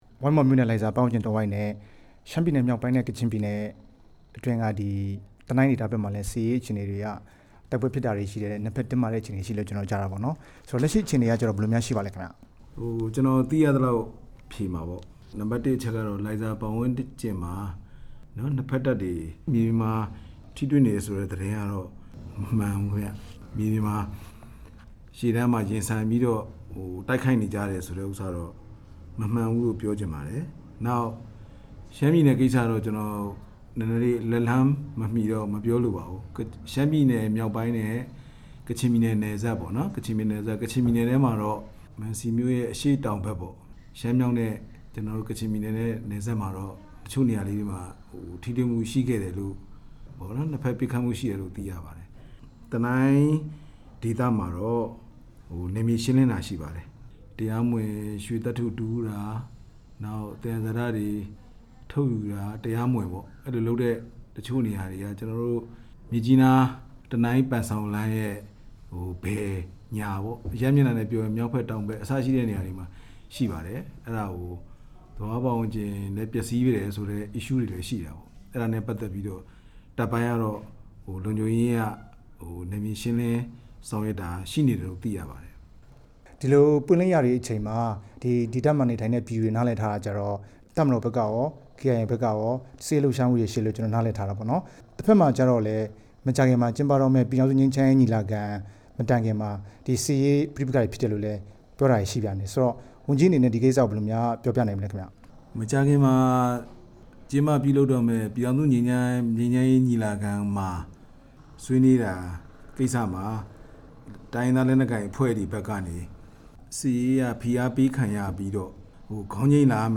ကချင်ပြည်နယ် နယ်လုံဝန်ကြီး ဗိုလ်မှူးကြီး သူရမျိုးတင်နဲ့ မေးမြန်းချက်